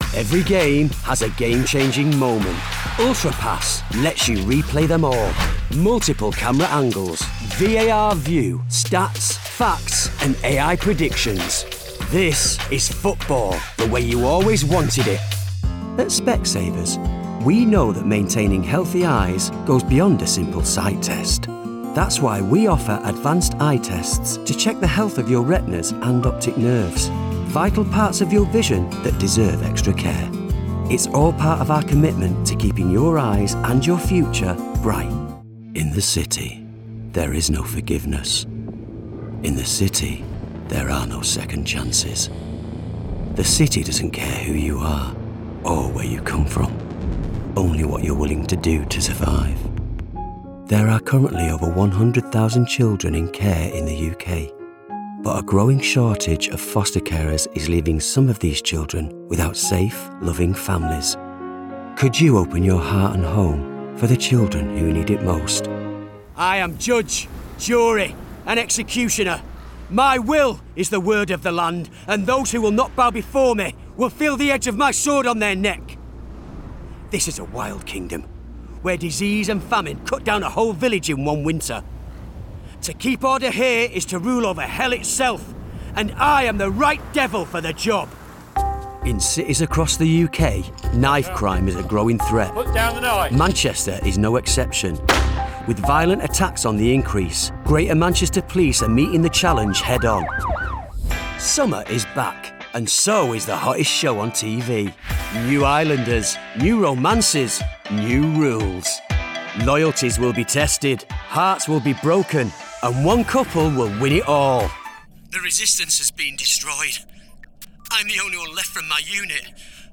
Commercial Showreel
Known for their honest delivery and down-to-earth charm, they bring scripts to life with heartfelt narration and compelling character performances.
Male
Manchester
Gravitas
Husky (light)